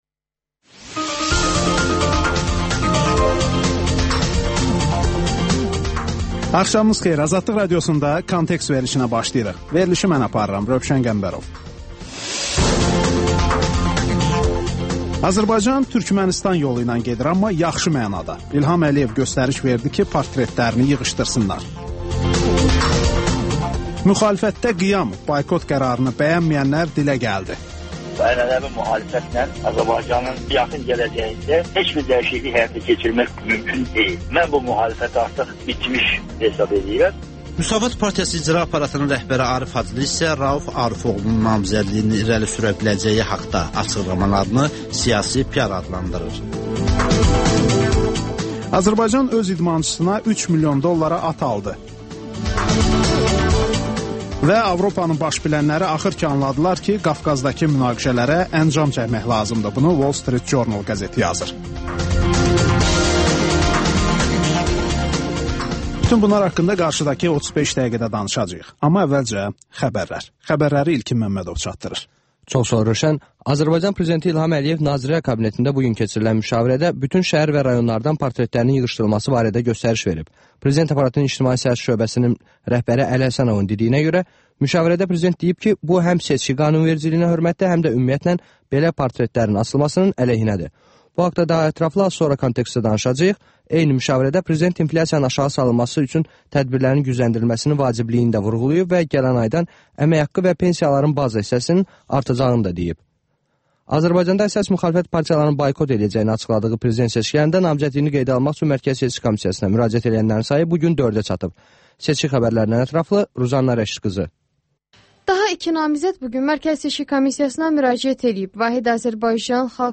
Xəbərlər